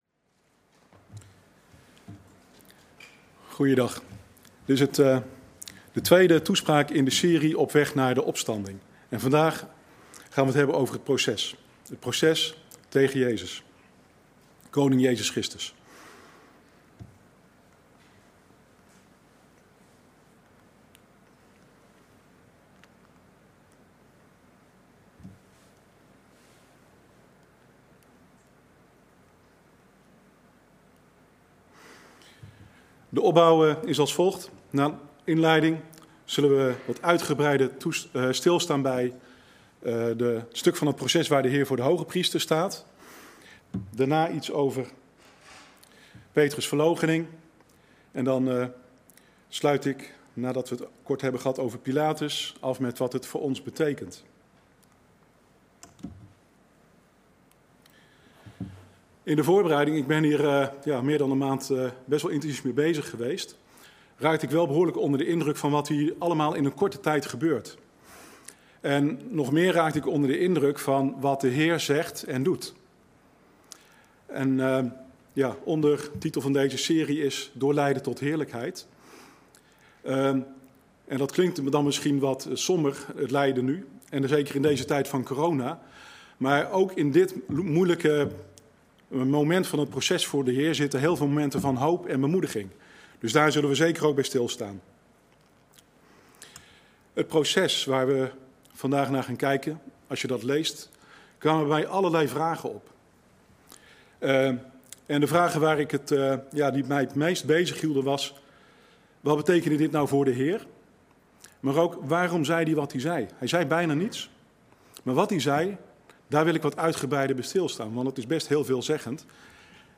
tweede toespraak onderweg naar Pasen